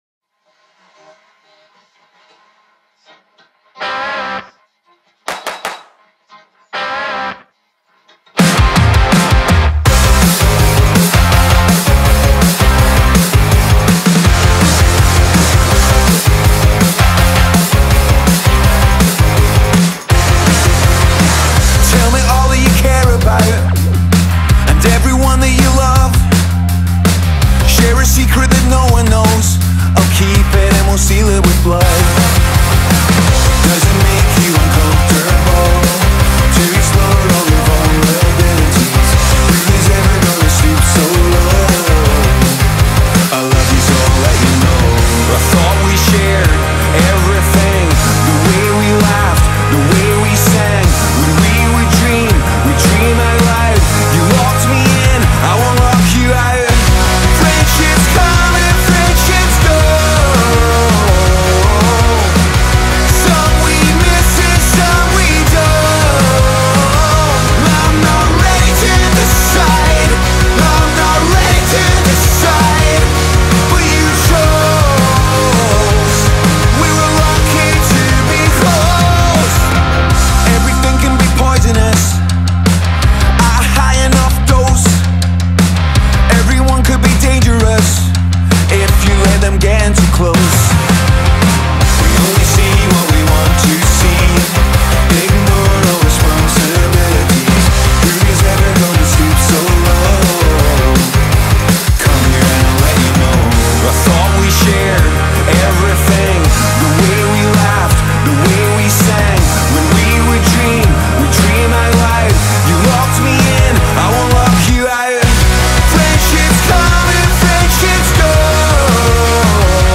The song moves unpredictably but never feels lost.